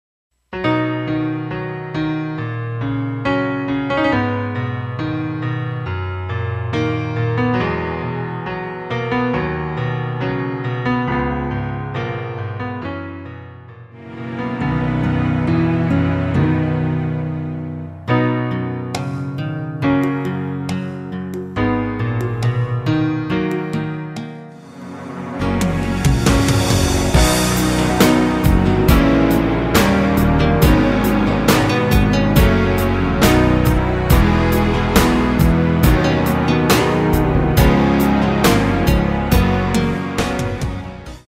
원키에서(-5)내린 MR입니다.
원곡의 보컬 목소리를 MR에 약하게 넣어서 제작한 MR이며